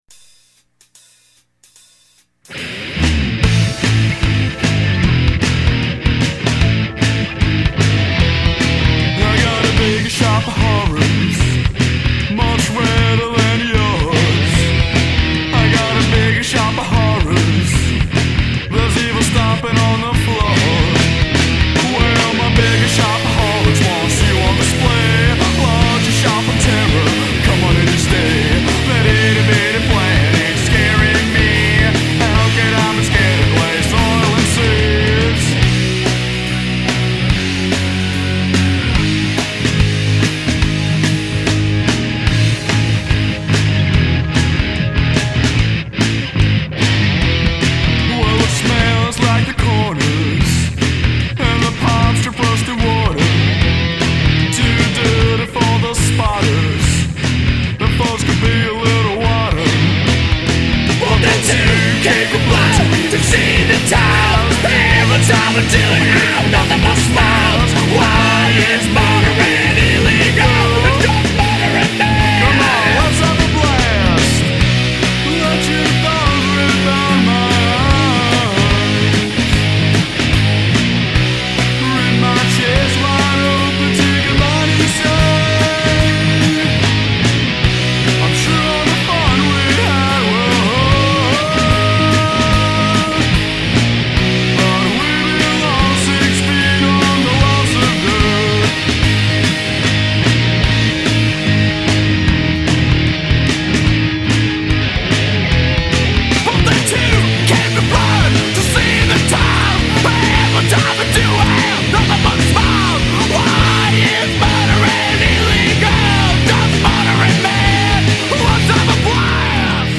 underground horror punk
their vicious guitars and rhythmic assaults